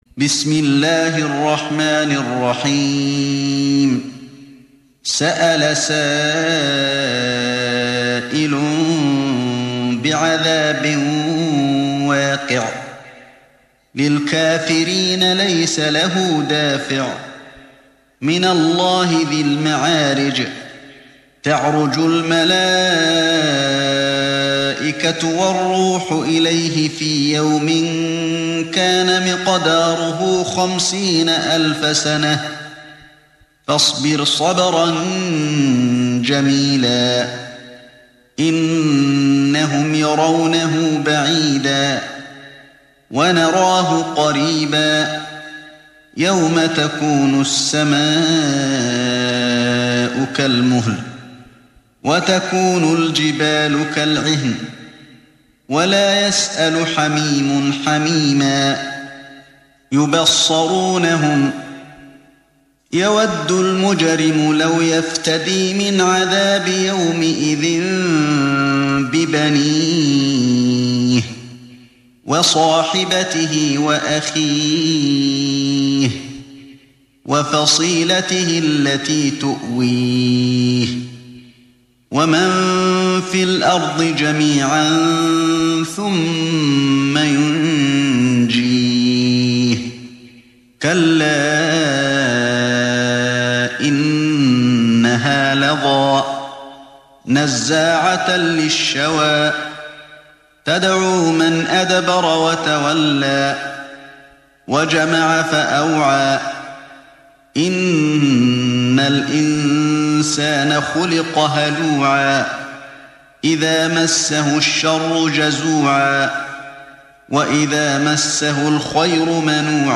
تحميل سورة المعارج mp3 بصوت علي الحذيفي برواية حفص عن عاصم, تحميل استماع القرآن الكريم على الجوال mp3 كاملا بروابط مباشرة وسريعة